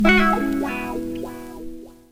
Sound (SoulGuitar).wav